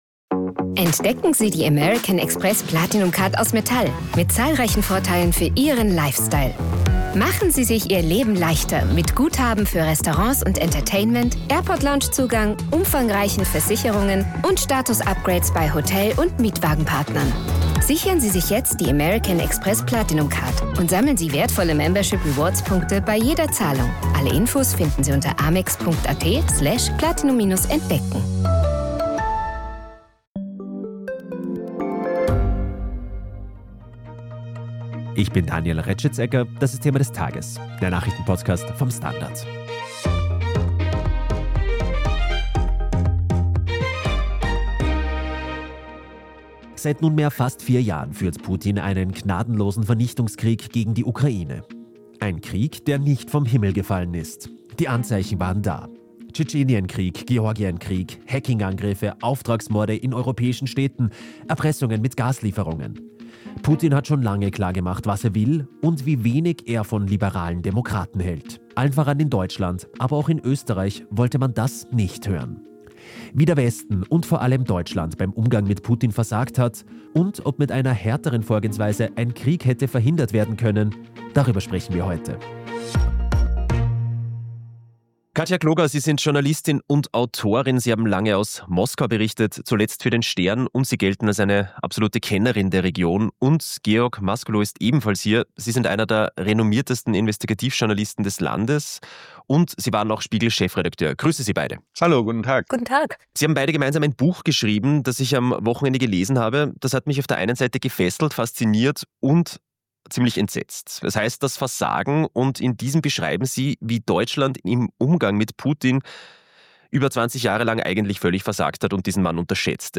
Der STANDARD hat mit ihnen gesprochen.